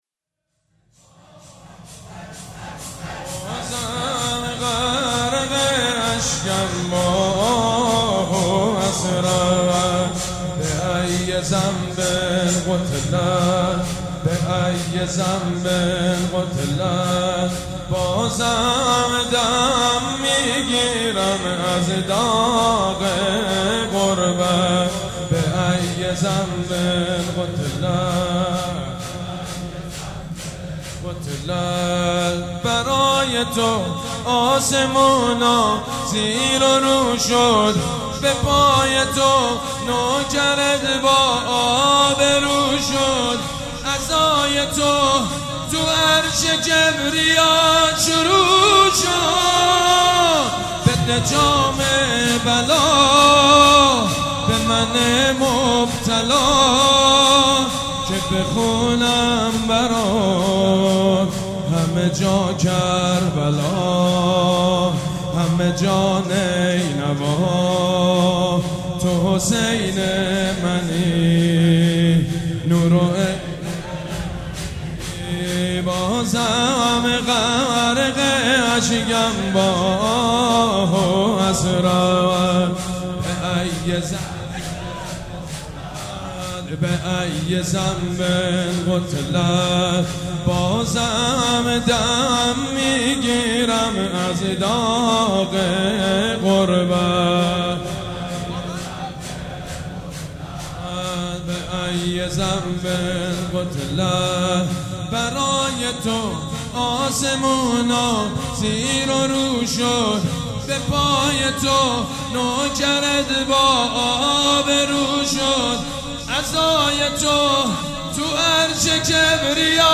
مـراسـم سیاه پوشان
شور
مداح
حاج سید مجید بنی فاطمه